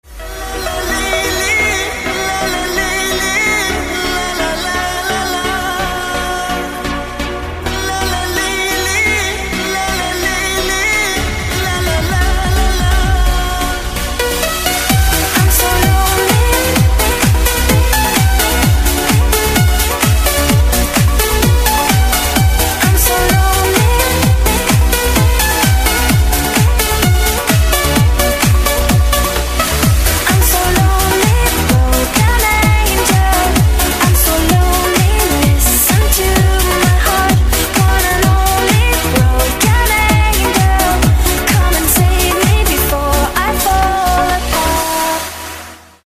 мужской вокал
громкие
женский вокал
dance
Electronic
EDM
Trance